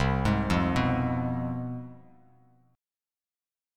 CM7sus4#5 chord